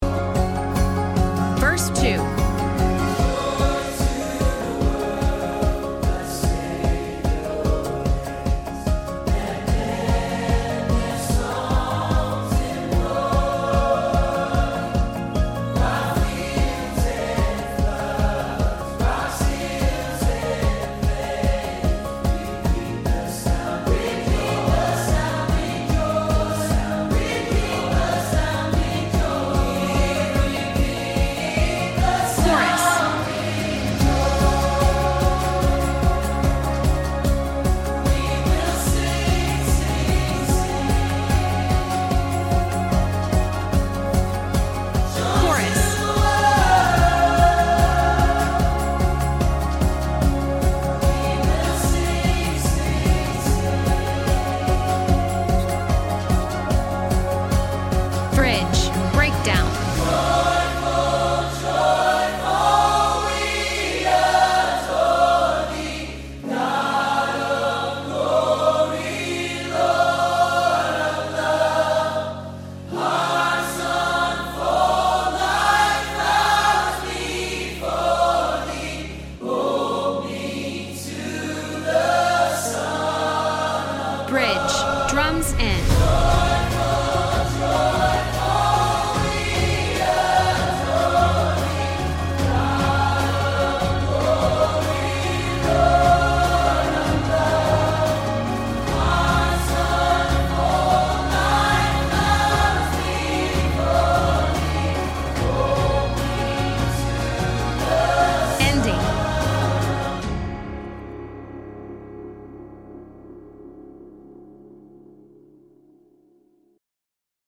Christmas Choir
Updated Joy to the World Choir Track.mp3